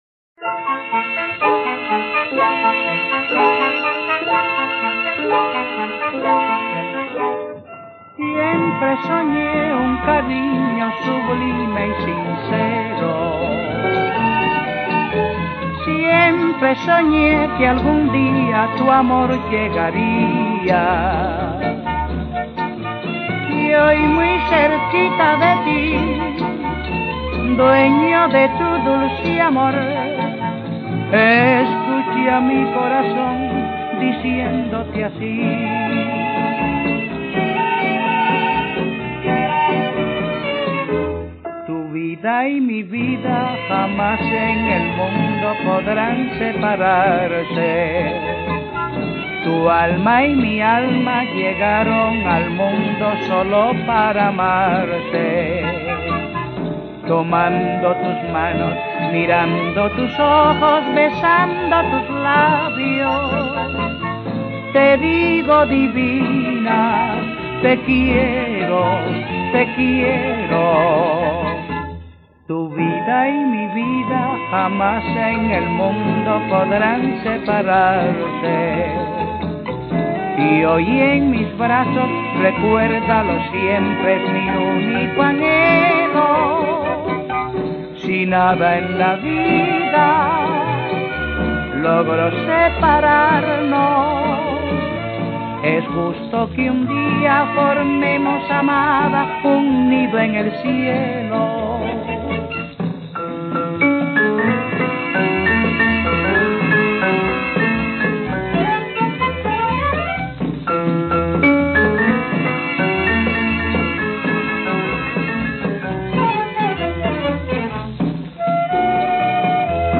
Escucha ahora 'Historia de una canción' del 3 de febrero de 2021 en "Herrera en COPE", presentado por Carlos Herrera
A estas horas de la mañana en "Historia de una canción" un buen bolero seguramente puede a usted ponerle en el estado de emoción necesario para afrontar el día y hoy nos lo trae el inapelable, grandioso Antonio Machín.